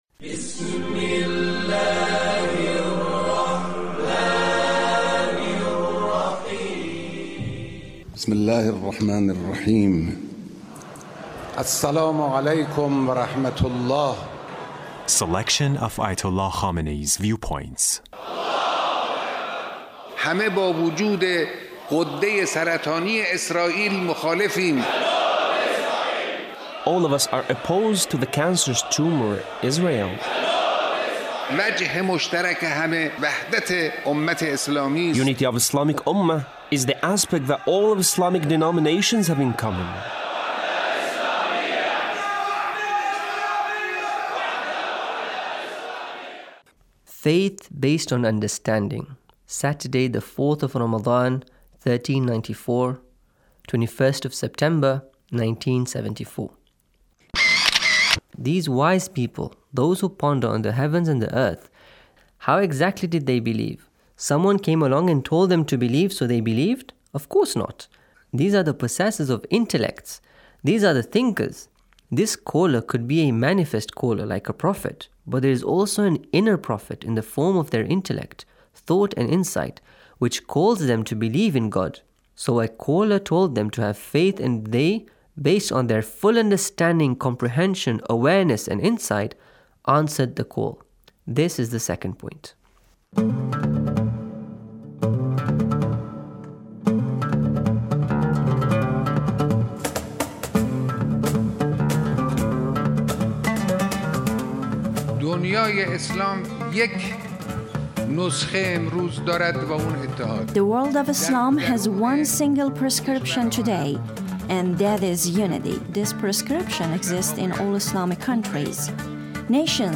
Leader's Speech (1556)
Leader's Speech on Taqwa